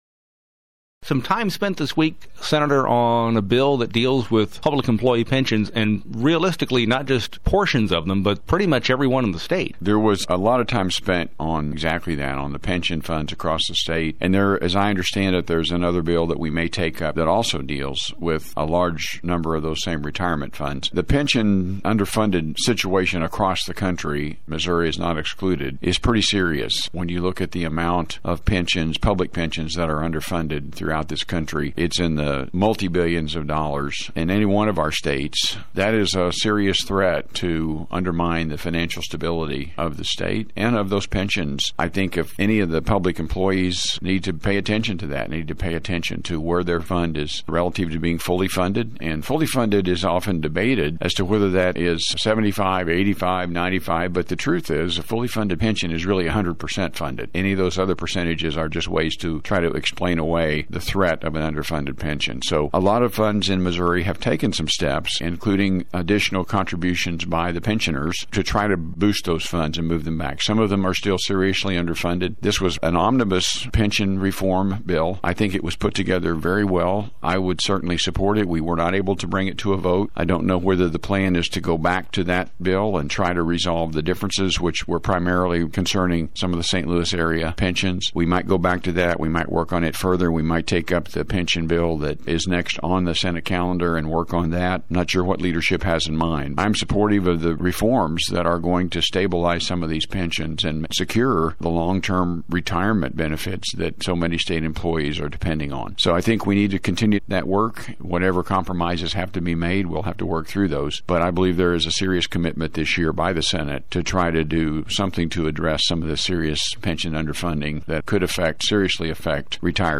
JEFFERSON CITY — State Sen. Ed Emery, R-Lamar, discusses House Bill 2044, a measure that would make changes to public employee pensions. He also discusses upcoming Missouri Senate action on the state’s Fiscal Year 2019 operating budget, which could start next week.